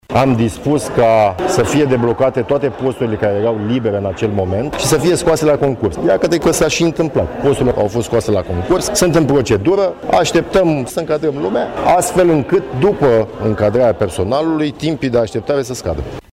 Asigurarea vine din partea , ministrului secretar de stat în Ministerul Afacerilor Interne, Mihai Dan Chirică, prezent la bilanțul Instituției Prefectului Brașov.
Acesta a declarat că vor fi scoase la concurs 5 posturi pentru completarea personalului existent: